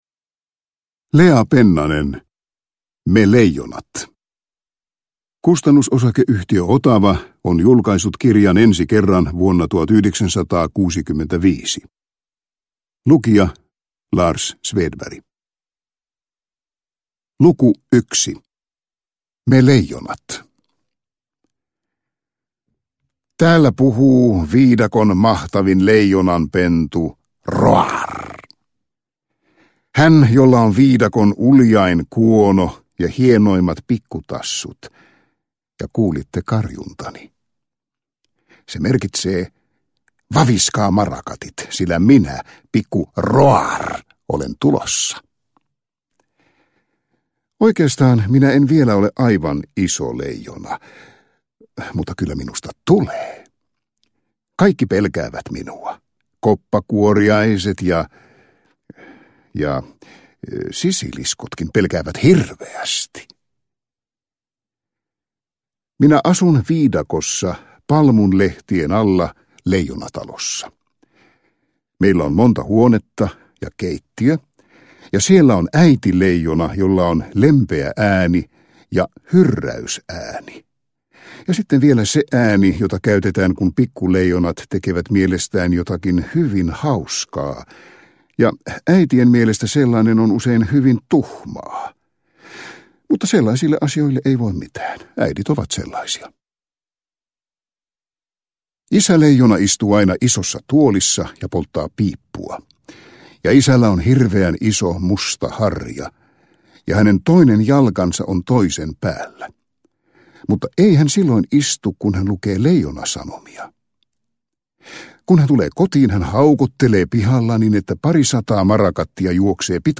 Me leijonat – Ljudbok – Laddas ner